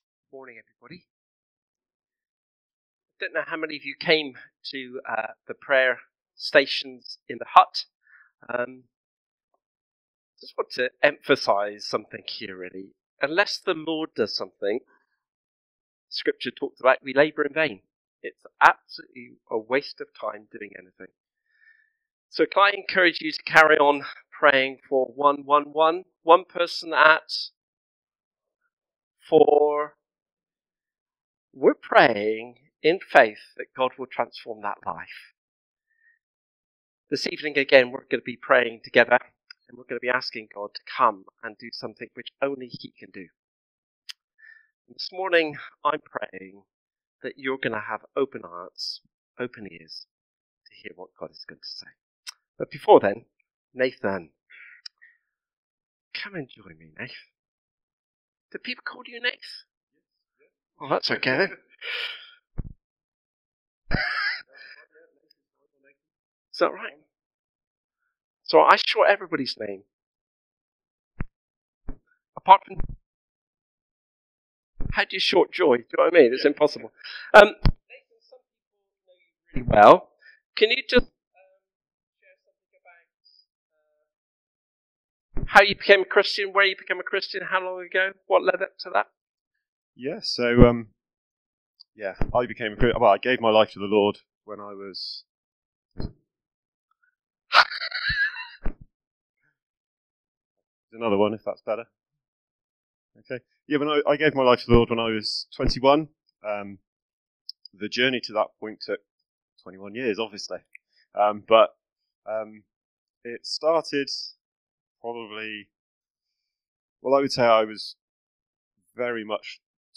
This sermon was preached on Sunday 1st June 2025 at a CBC Priory Street.